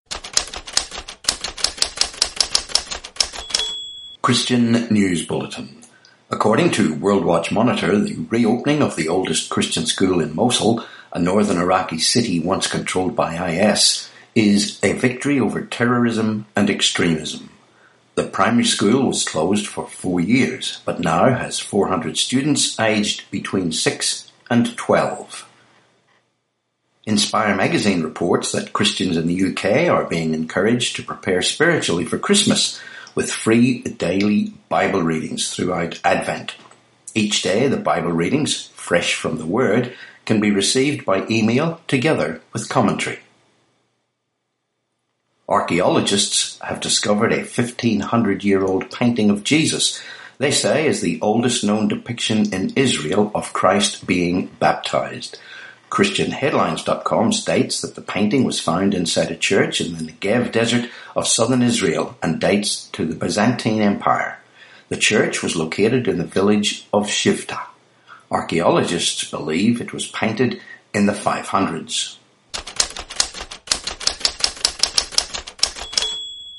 25Nov18 Christian News Bulletin